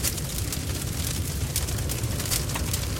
FireDead.ogg